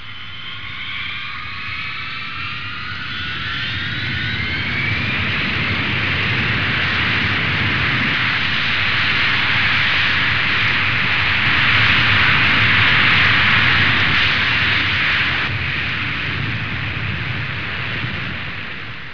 دانلود آهنگ طیاره 20 از افکت صوتی حمل و نقل
جلوه های صوتی
دانلود صدای طیاره 20 از ساعد نیوز با لینک مستقیم و کیفیت بالا